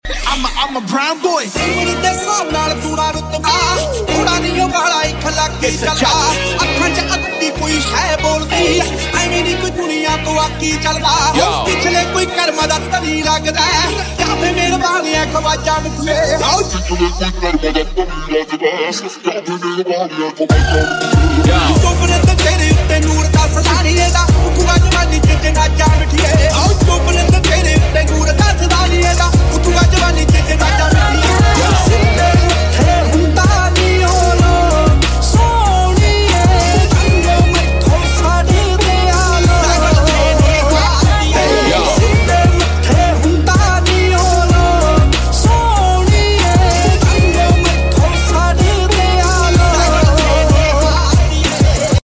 Toyota Camry 10inch display sound effects free download